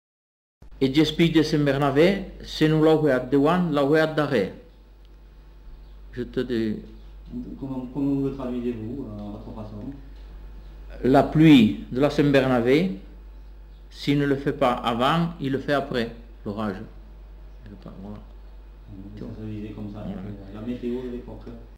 Lieu : Bagnères-de-Luchon
Genre : forme brève
Effectif : 1
Type de voix : voix d'homme
Production du son : récité
Classification : proverbe-dicton